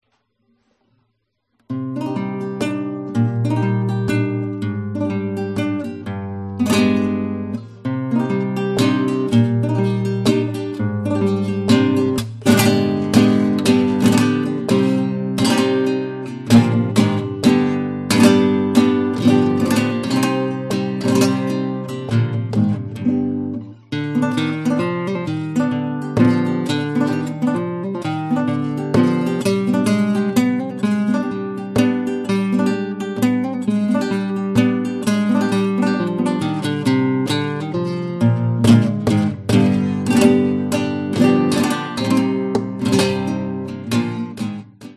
Flamenco
Have yourself a glas of Fino and enjoy Fandango (I'll warn you - it's rather short, as live always is...)
>> Fandango
einer meiner Lieblings-Palos hier mit eigener Intro.
fandango_45.mp3